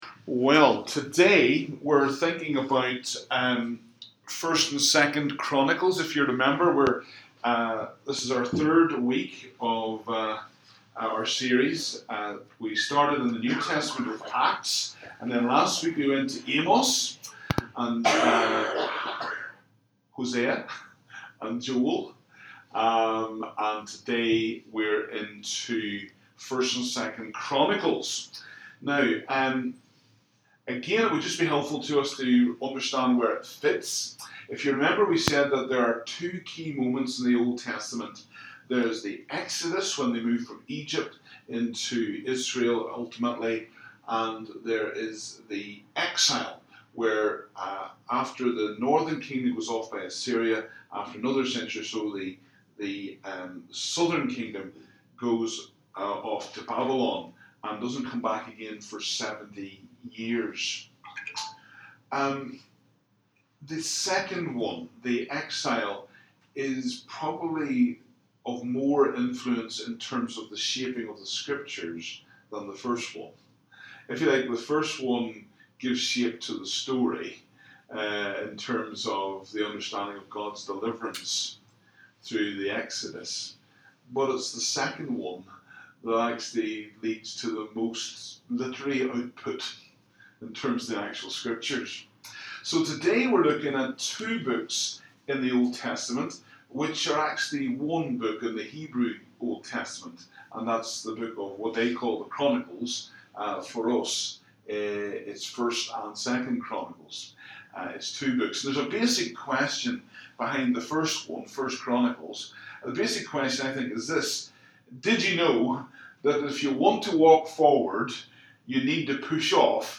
Download the live Session as an MP3 audio file